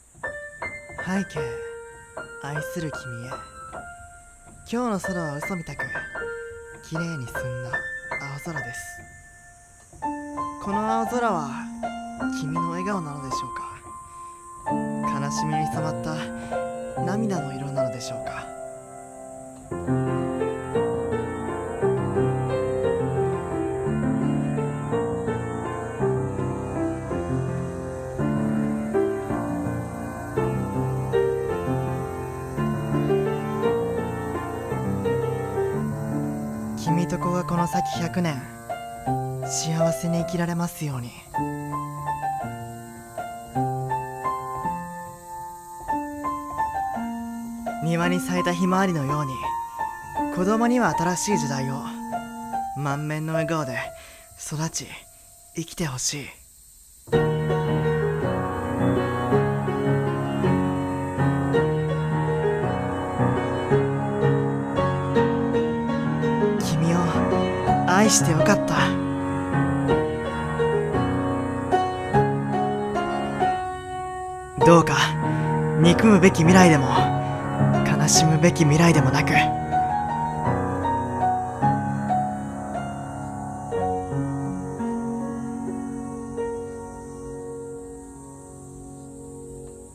コラボ声劇【君ガ空コソカナシケレ